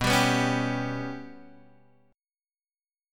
Bsus2#5 chord